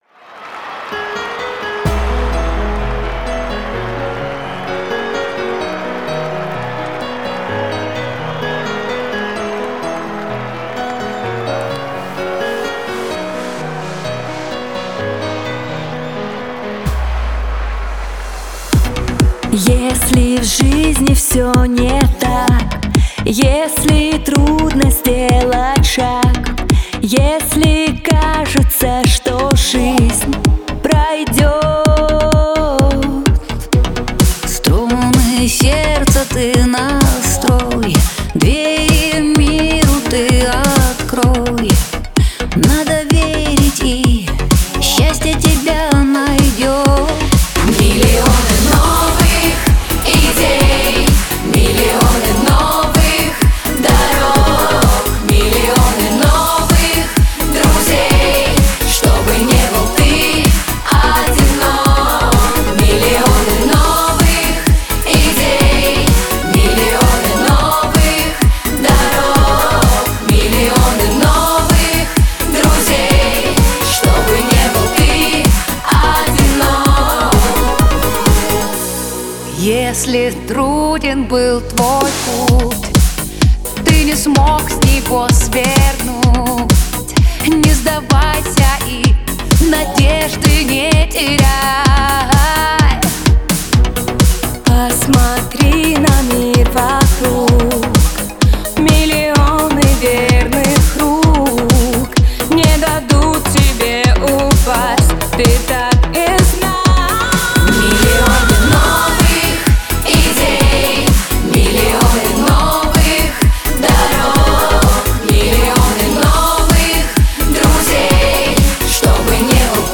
Жанр: Pop
Стиль: Europop, Vocal